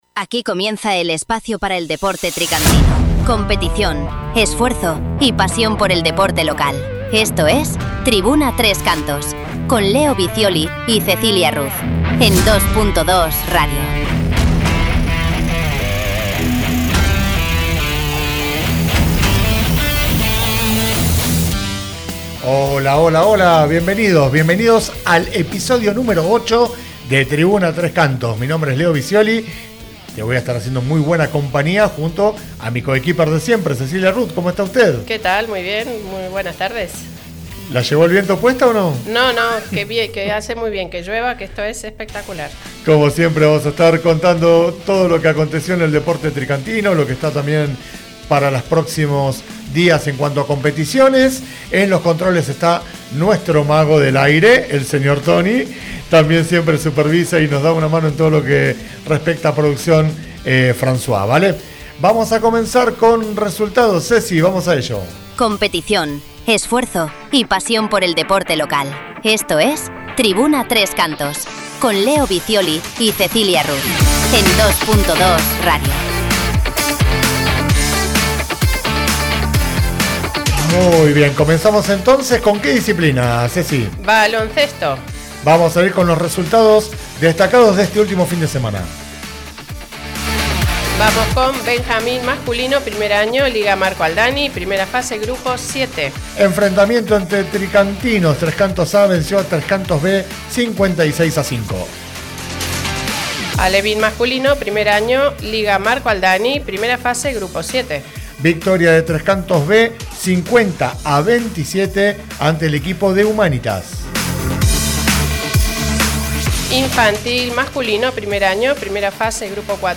Comienza el programa con las noticias de la actividad del fin de semana mencionando los resultados de competiciones de los deportistas y equipos tricantinos de Baloncesto, Fútbol, Balonmano y Padel. En el bloque 2 entrevistamos